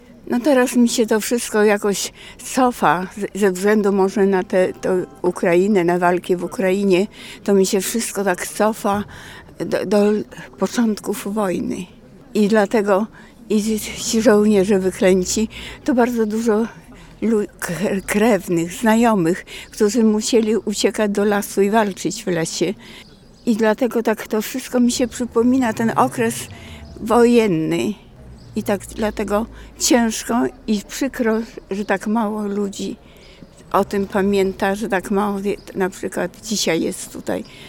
Zielona Góra uczciła Narodowy Dzień Pamięci „Żołnierzy Wyklętych”.